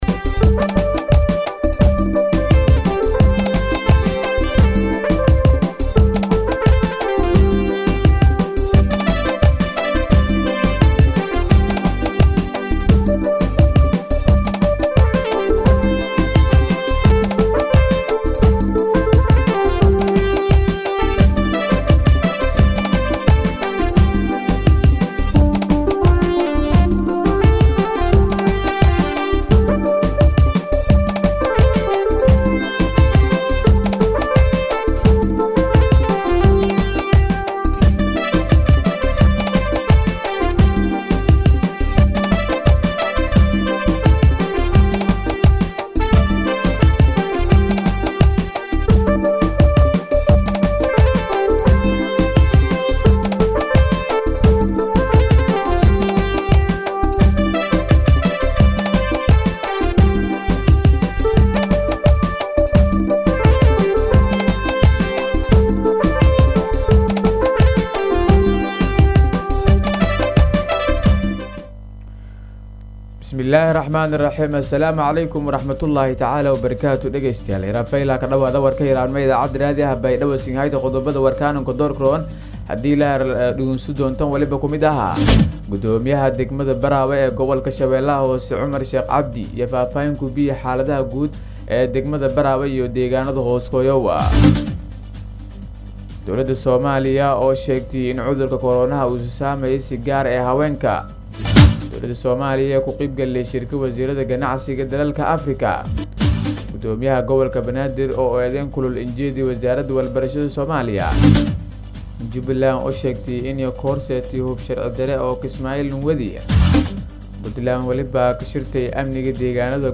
DHAGEYSO:- Warka Subaxnimo Radio Baidoa 9-7-2021